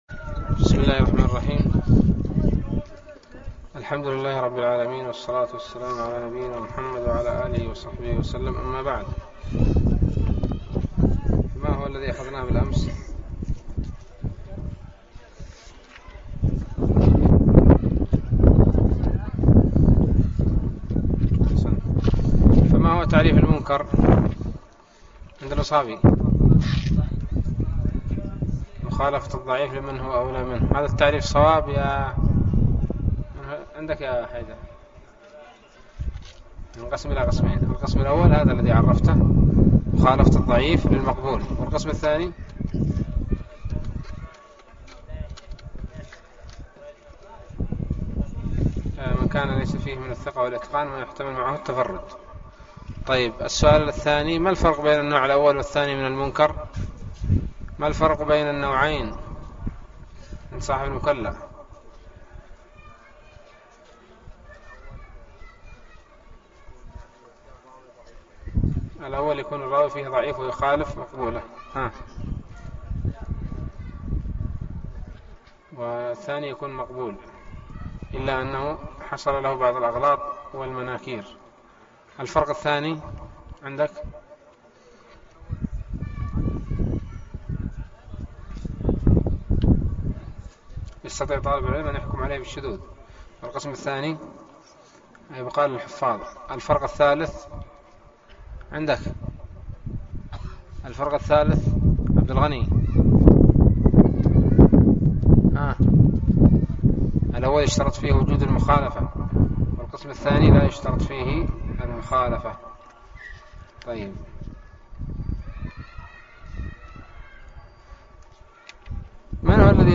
الدرس الرابع والعشرون من الباعث الحثيث